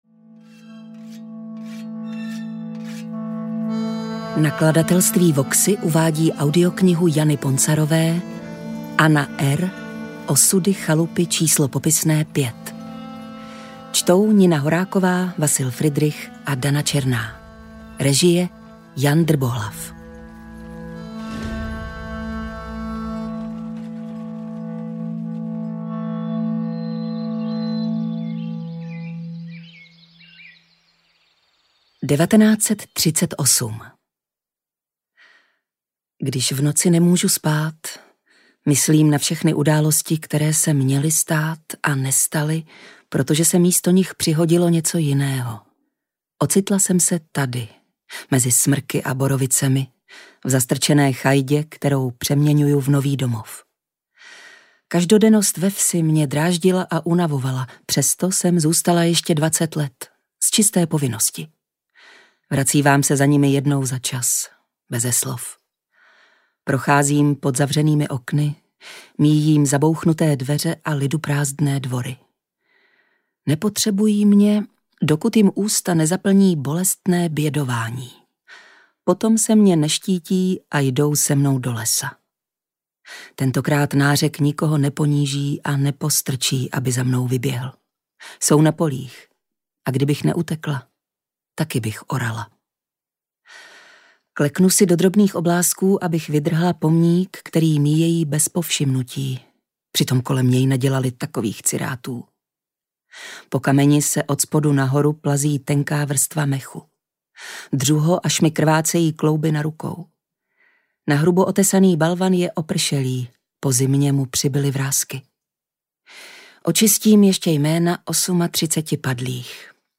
Anna R. audiokniha
Ukázka z knihy